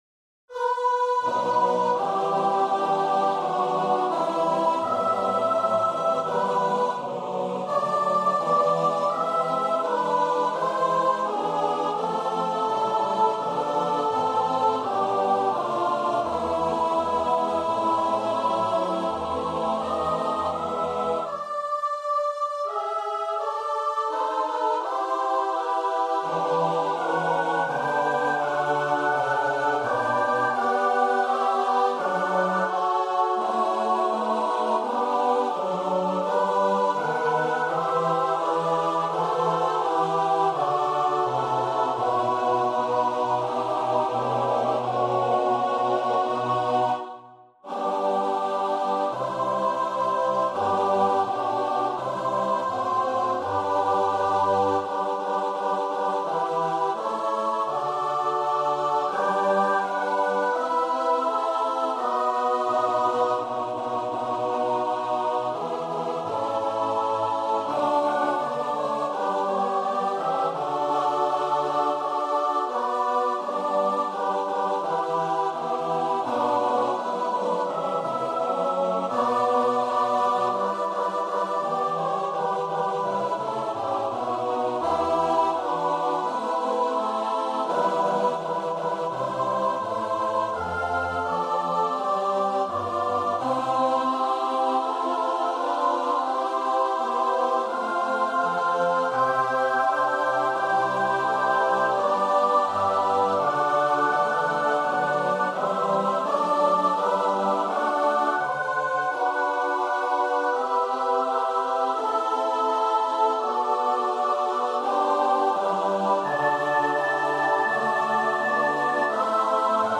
Free Sheet music for Choir (SATB)
Classical (View more Classical Choir Music)